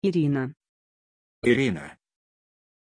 Pronunția numelui Irina
pronunciation-irina-ru.mp3